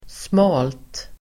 Ladda ner uttalet
Uttal: [smal:t]